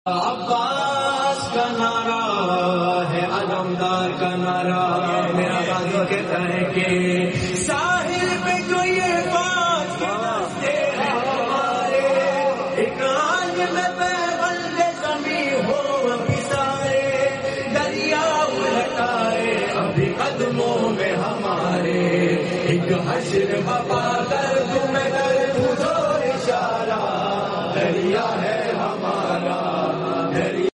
Recitation from Netherlands 🇳🇱 2025 Darya Hai Hamara, Abbas Ka Sound Effects Free Download.